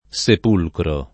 sepolcro [Sep1lkro] s. m. — raro latinismo ant. sepulcro [